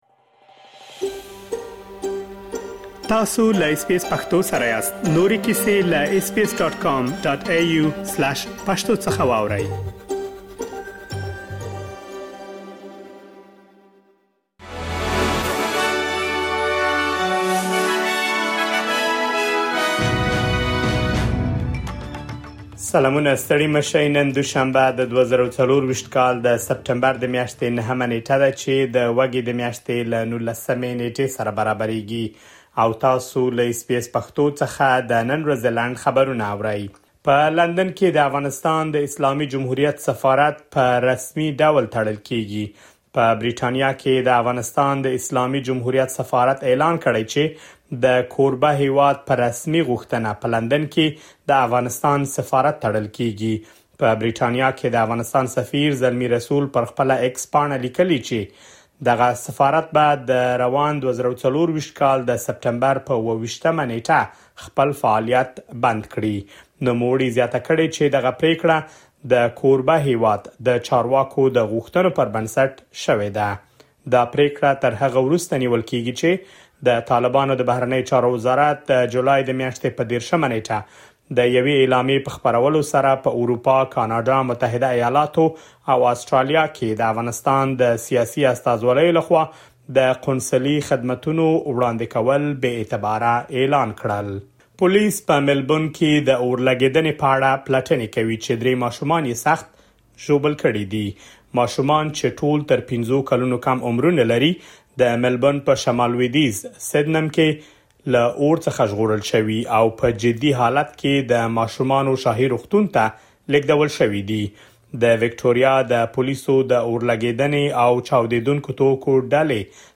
د اس بي اس پښتو د نن ورځې لنډ خبرونه|۹ سپټمبر ۲۰۲۴
د اس بي اس پښتو د نن ورځې لنډ خبرونه دلته واورئ.